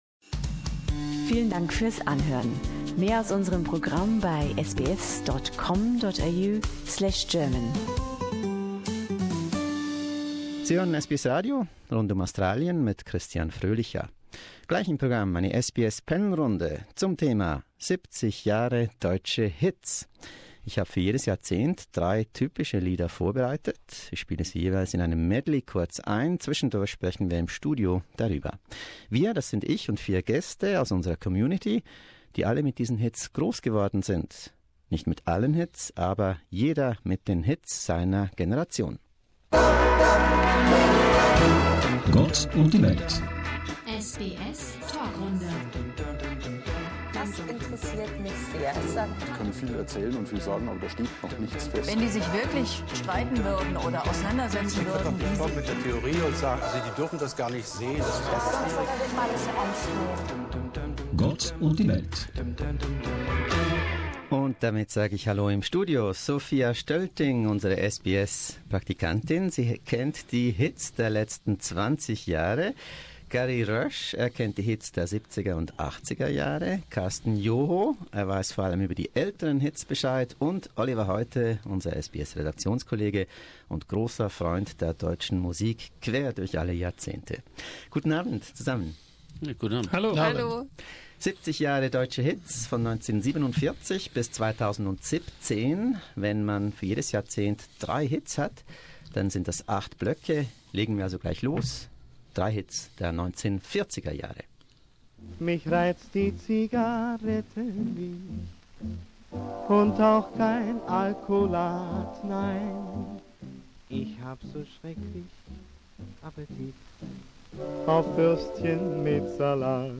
SBS panel discussion - 70 years of German hits